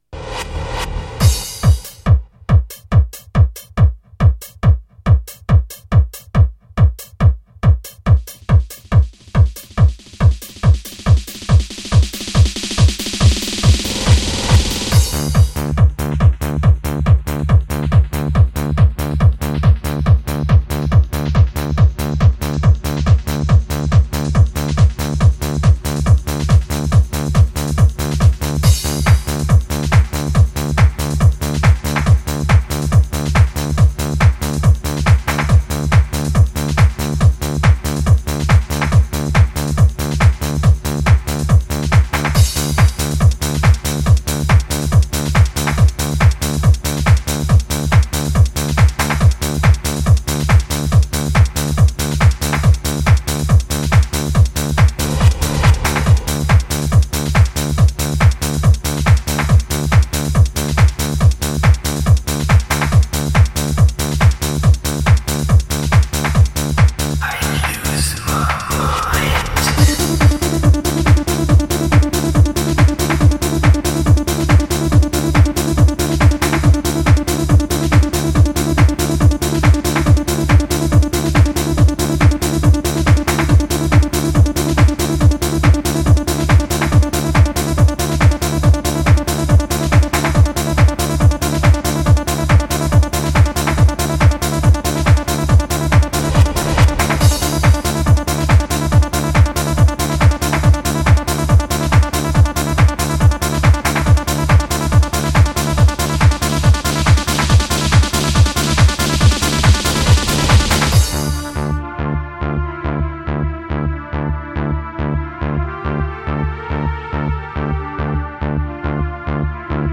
Жанр: HardTrance